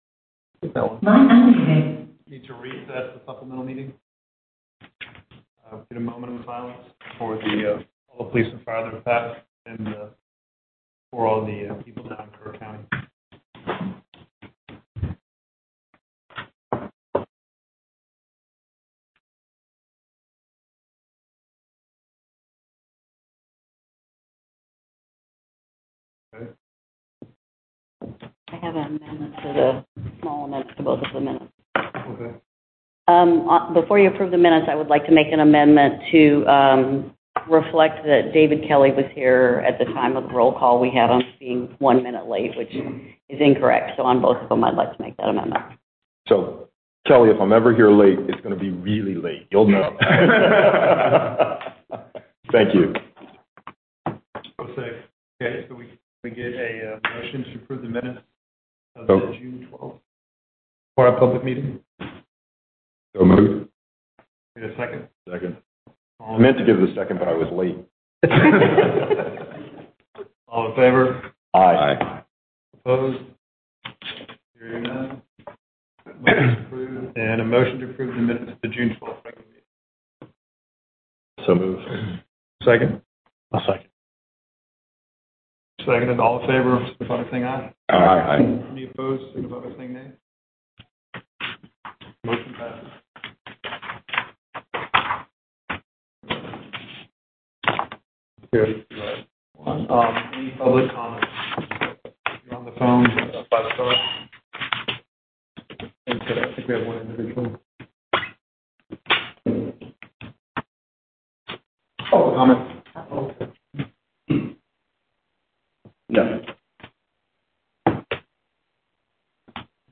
Teleconference Audio 1